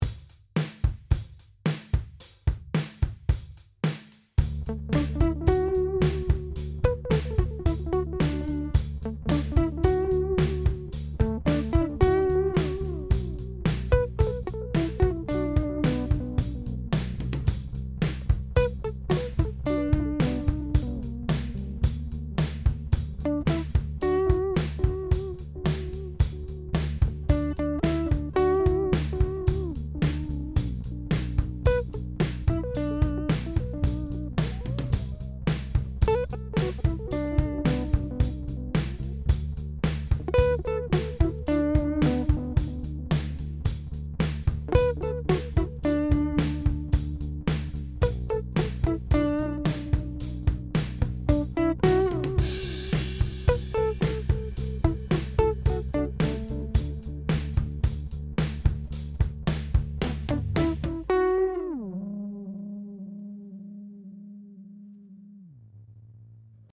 The Mu-Tron ECHO-TRON is a digital delay with an analog vibe that emulates vintage tape echo units.
From slapback echo to longer, lush delays, the ECHO-TRON provides a transparent ambience that inspires the player with spatiality.
Echo-Trom Demo Band Clip
DelayBand.mp3